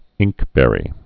(ĭngkbĕrē)